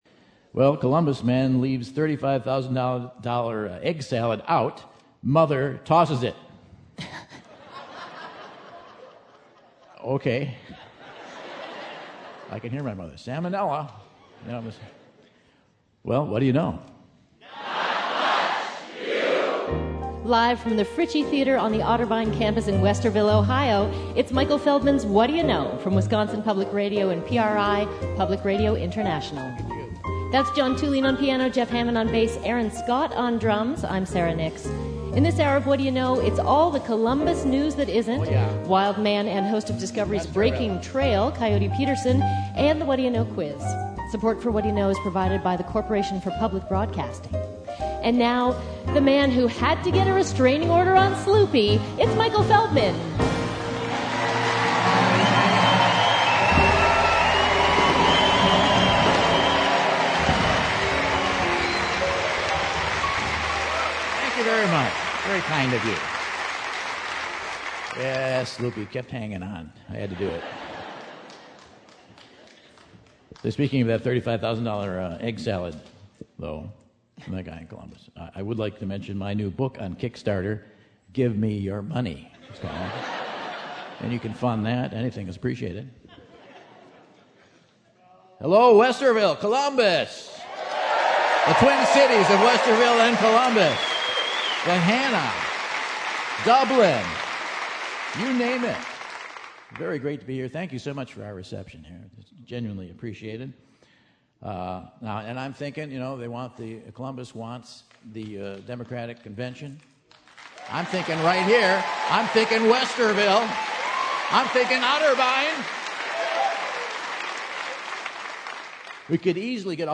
Michael takes Ohio to task in the Central Ohio version of All the News That Isn't from the Otterbein University campus.